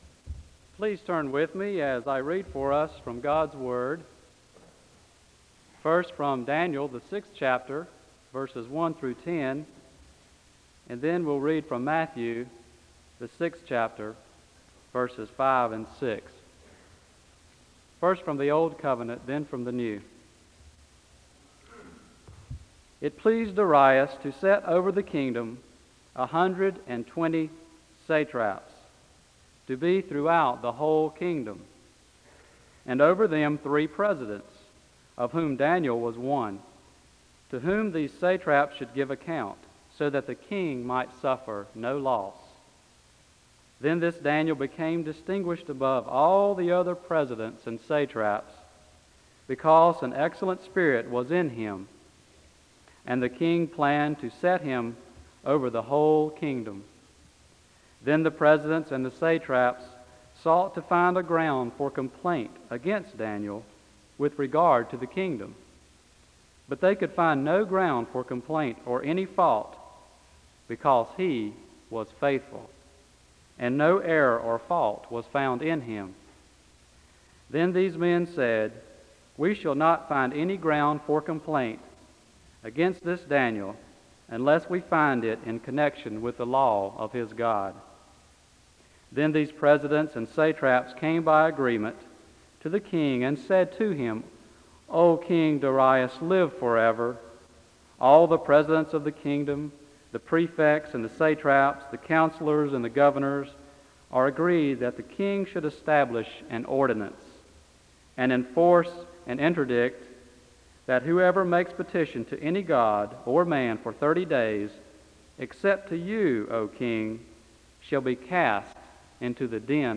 The service begins with a Scripture reading from Daniel 6:1-10 and Matthew 6:5-6 (0:00-3:32). The choir sings a song of worship (3:33-6:08).
There is a moment of prayer to conclude the service (20:29-21:16).
Location Wake Forest (N.C.)